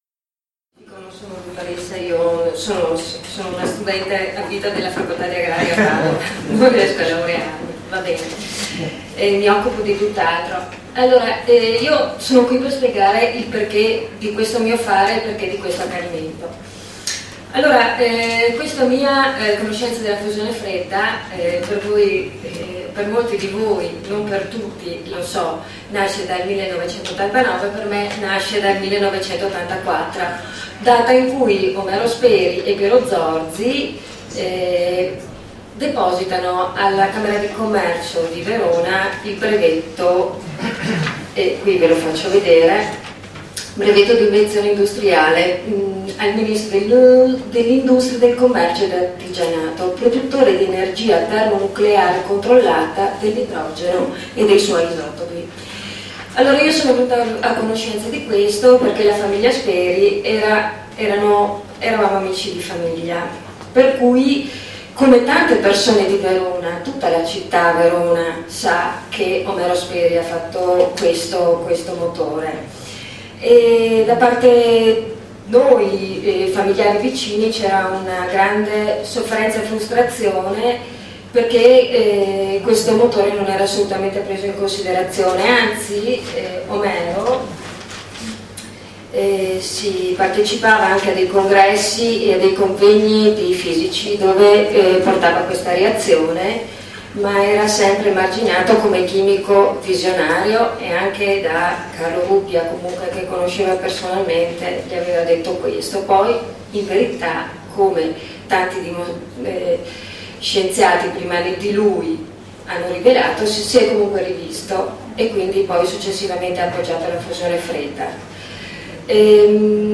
Convegno tenutosi il 2 luglio 2012 a Roma presso la Sala della Mercede della Camera dei Deputati, organizzato dall'Università degli Studi di Palermo.